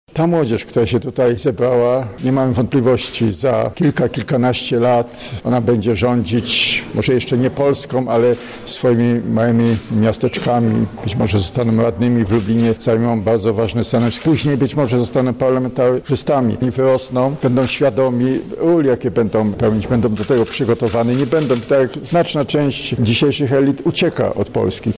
Wiążemy z tymi młodymi ludźmi wielkie nadzieje – mówi prezes Instytutu Pamięci Narodowej Jarosław Szarek: